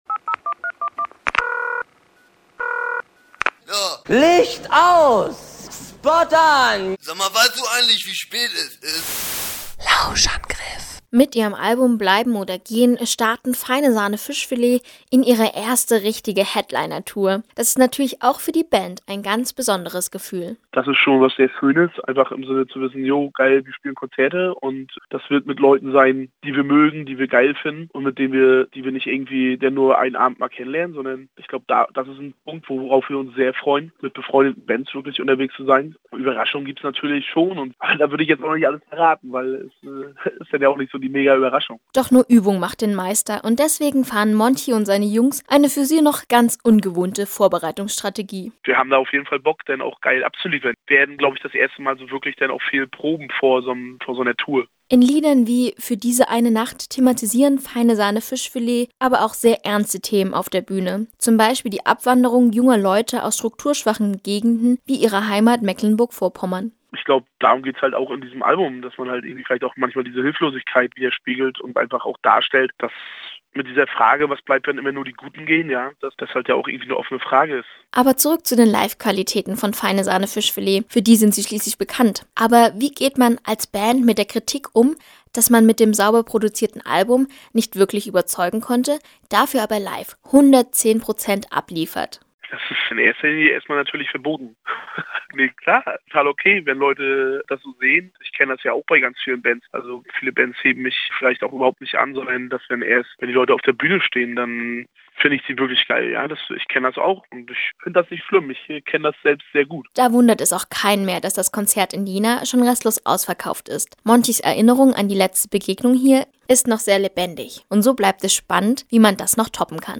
Wir haben vorab mit Sänger Monchi über Jena, Abwanderung und die Livequalitäten einer Band in unserem neuesten Lausch-am-Griff gesprochen.